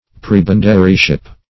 \Preb"en*da*ry*ship\